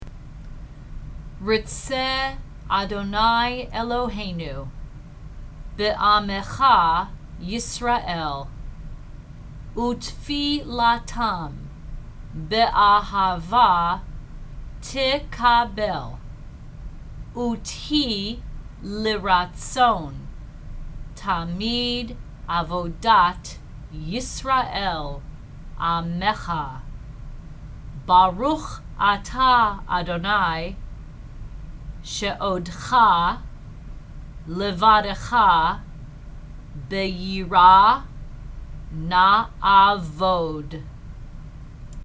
rtzei_spoken.mp3